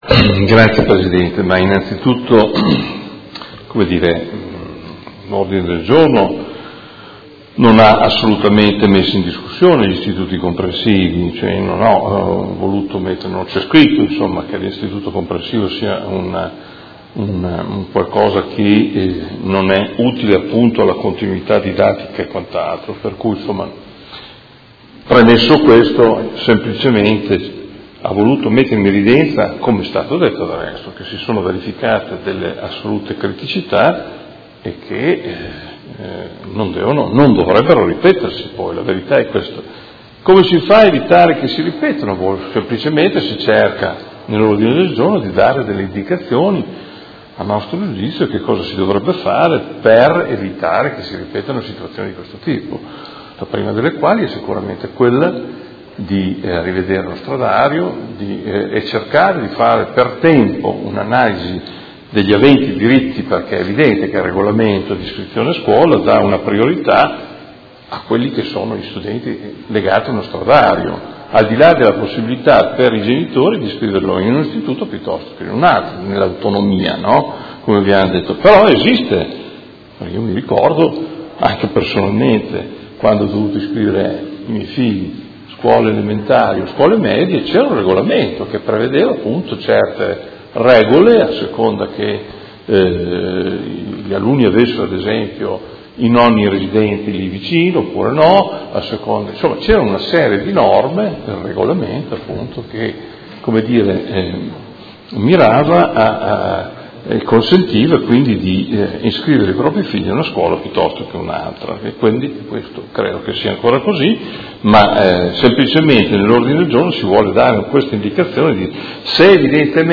Adolfo Morandi — Sito Audio Consiglio Comunale
Seduta del 26/06/2017.